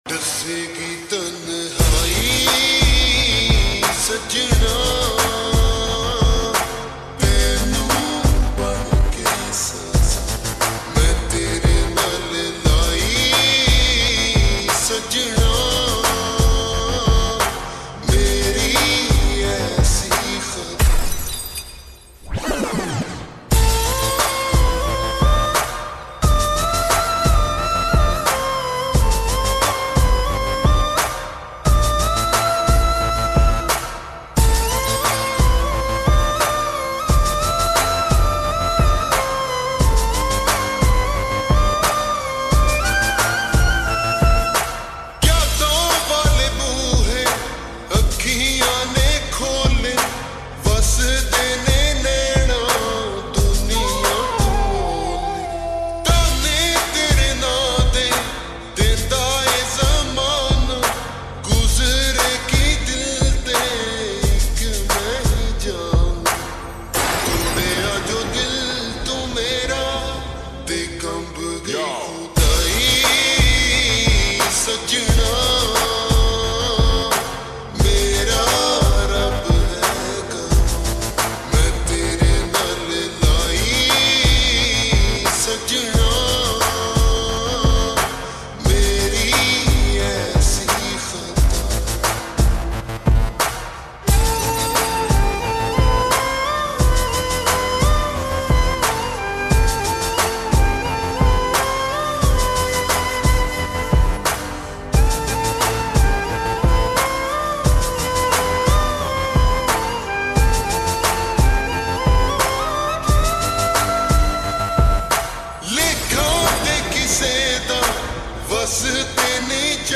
slowed x reverb